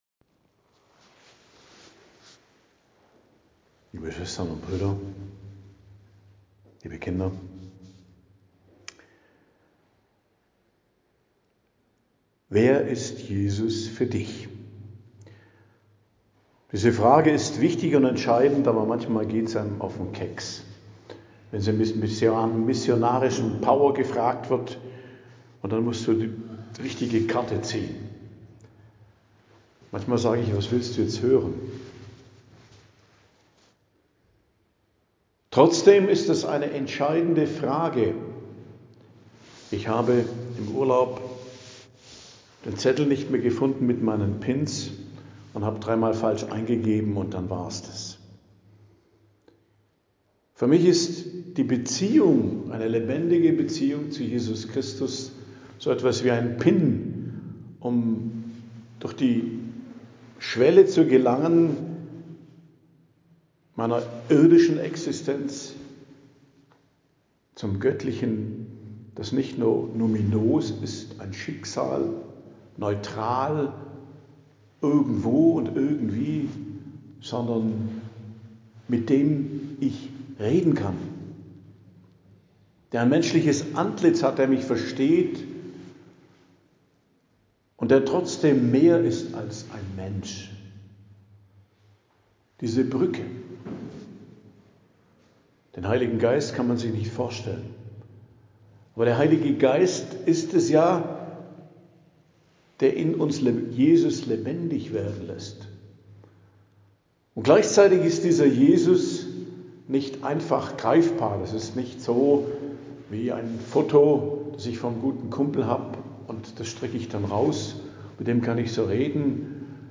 Predigt am Dienstag der 23. Woche i.J., 9.09.2025 ~ Geistliches Zentrum Kloster Heiligkreuztal Podcast